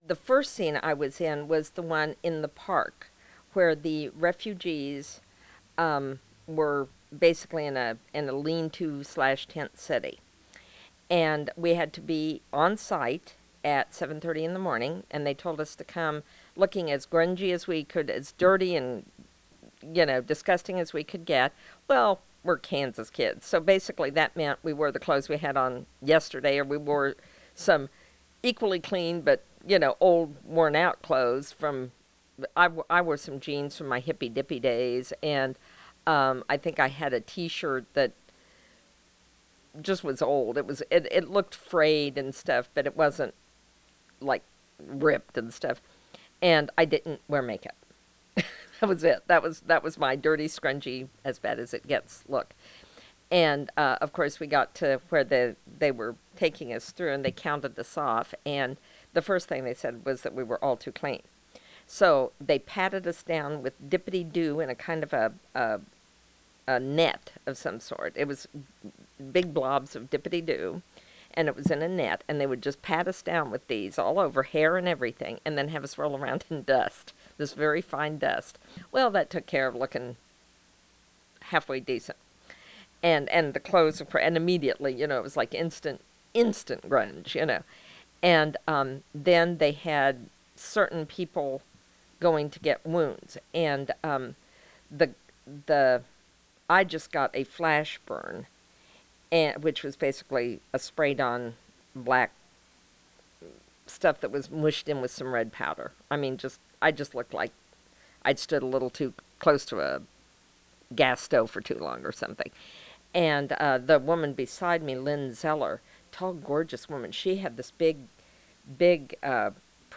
The interview was conducted at the Watkins Museum of History on June 28, 2012.
Oral History